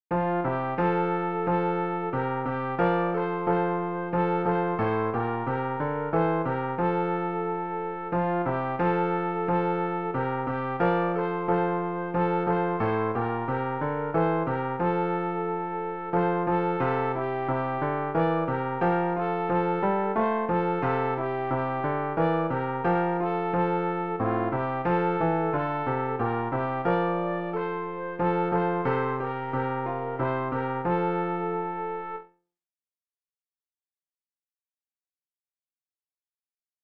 rg-531-weisst-du-wie-viel-sternlein-stehen-sopran.mp3